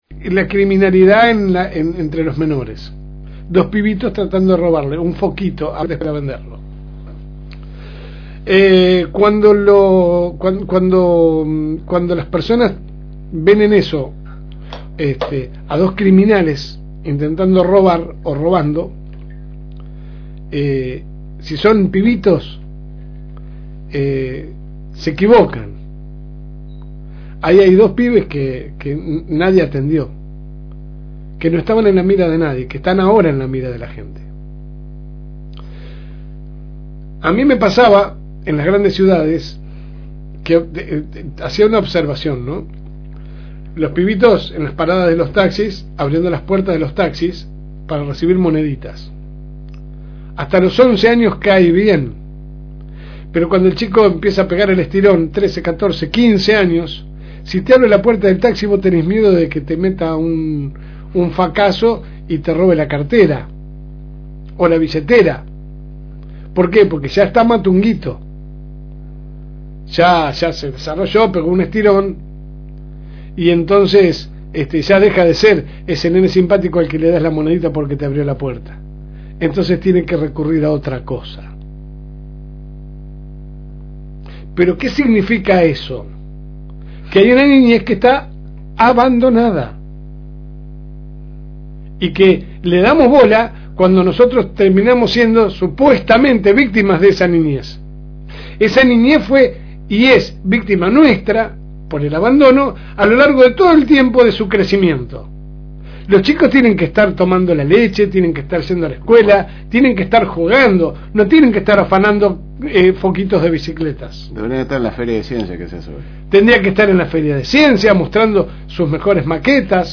Que sale por el aire de la FM Reencuentro 102.9 de lunes a viernes de 10 a 12 HS